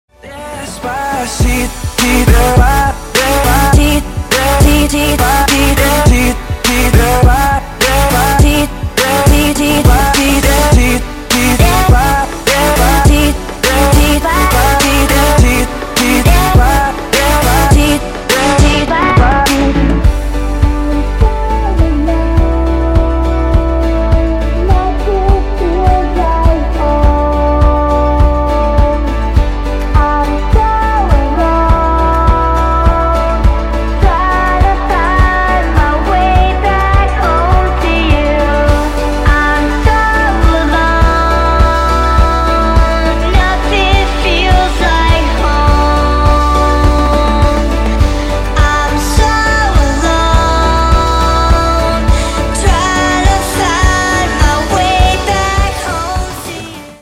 • Качество: 128, Stereo
женский вокал
dance
Electronic
EDM
club